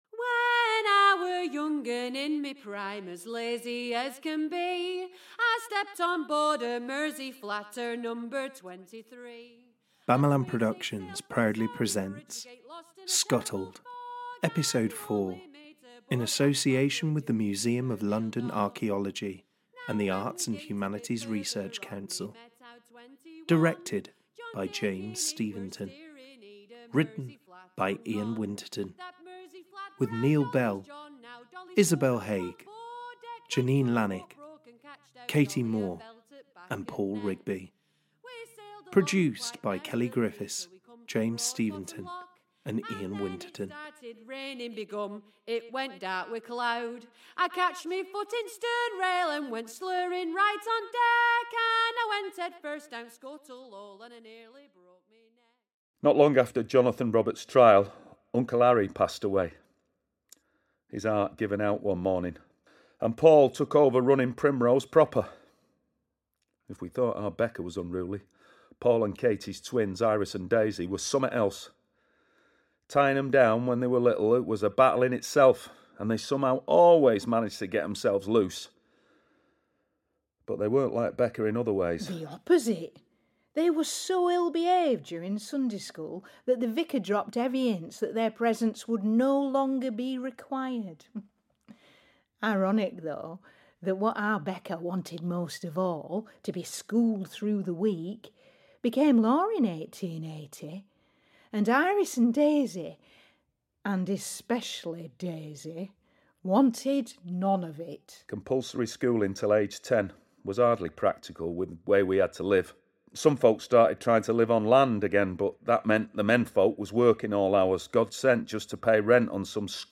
Recorded at: Oscillate Studios (Manchester), Jungle Studios (Soho, London), and Voltalab (Rochdale).